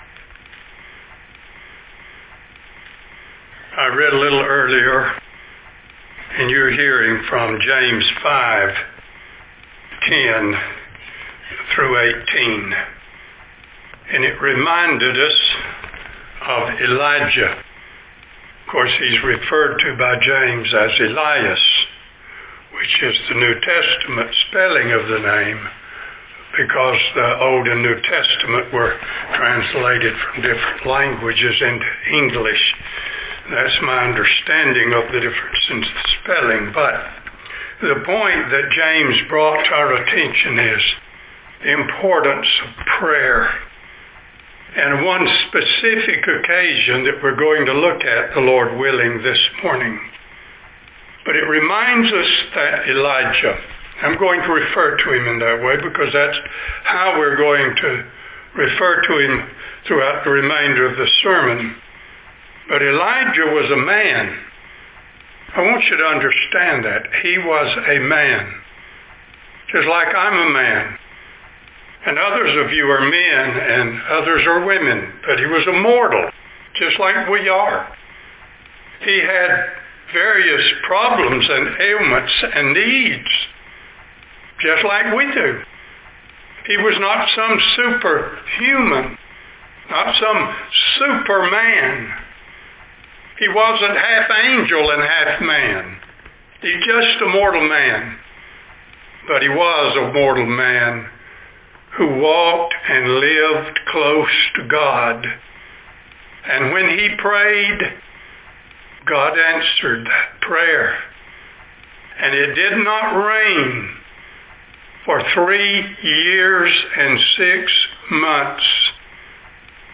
1 Kings 18:39, One God Sep 20 In: Sermon by Speaker